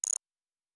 pgs/Assets/Audio/Sci-Fi Sounds/Interface/Digital Click 07.wav at master
Digital Click 07.wav